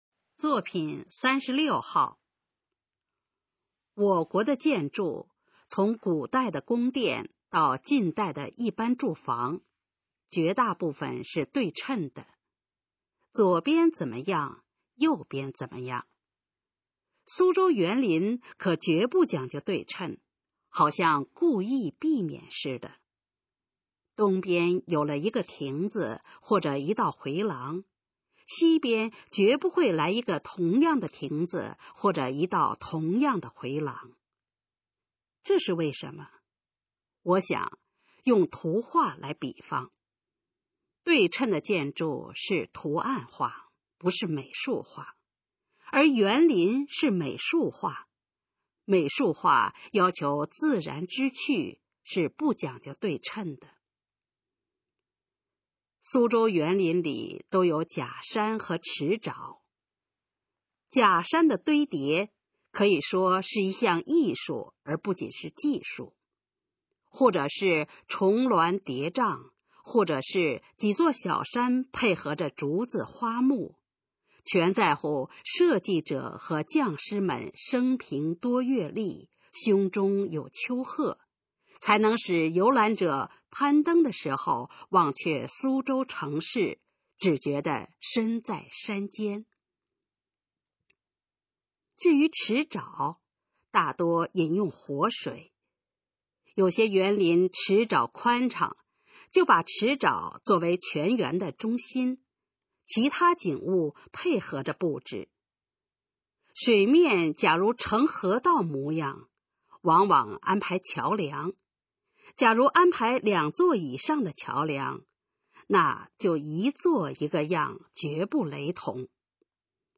《苏州园林》示范朗读_水平测试（等级考试）用60篇朗读作品范读